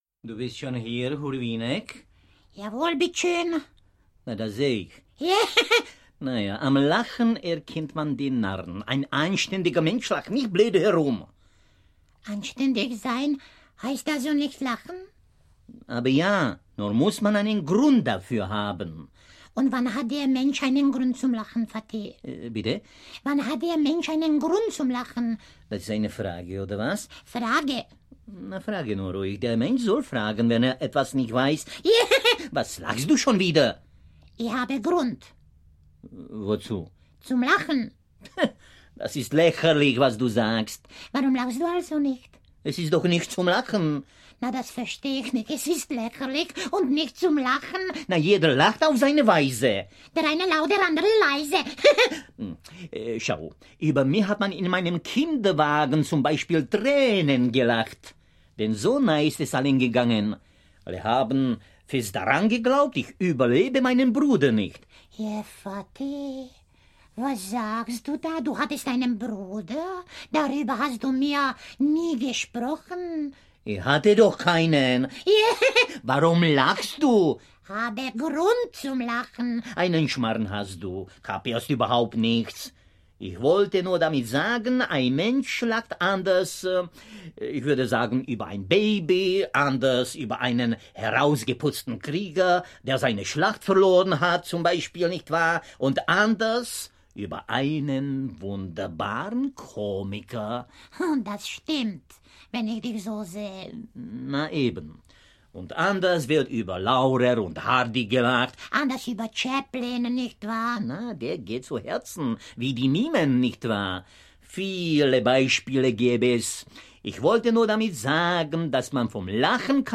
Audio knihaSpejbl's herzliche Metaferosen
Ukázka z knihy